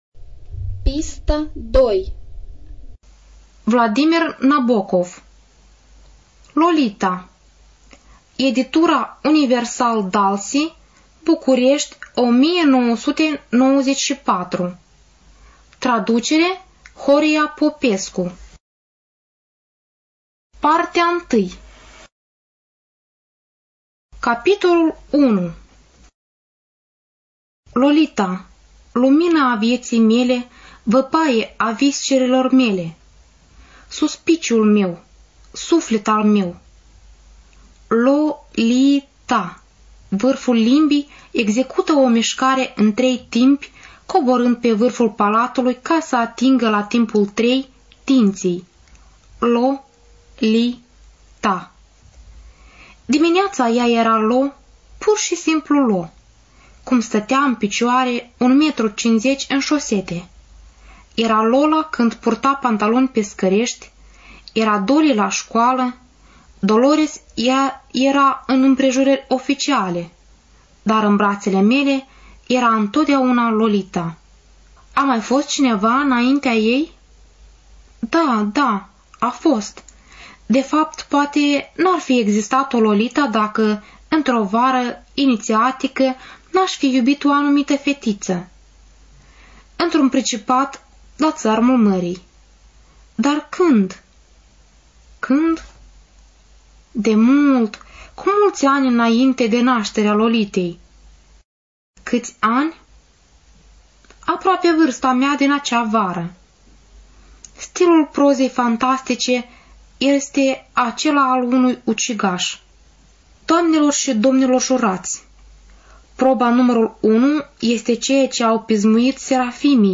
Студия звукозаписиНациональный Информационно-реабилитационный Центр Ассоциации Незрячих Молдовы